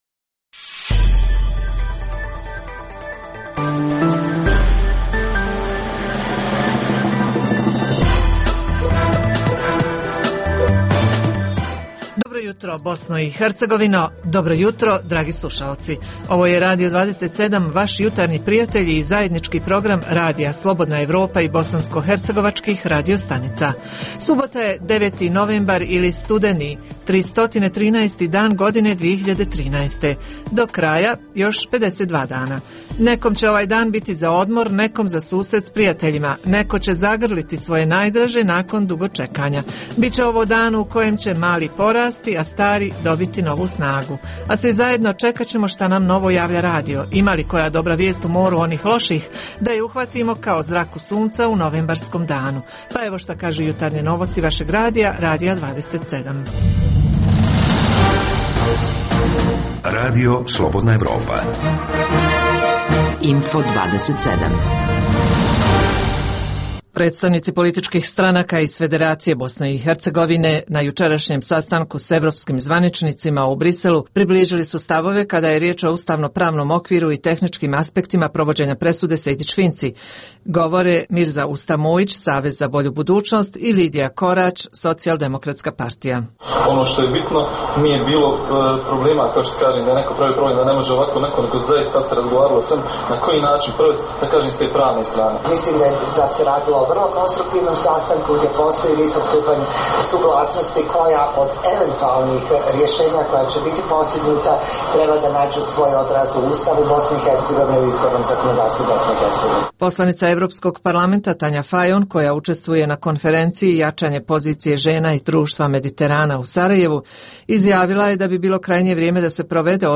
- Uz tri emisije vijesti, slušaoci mogu uživati i u ugodnoj muzici.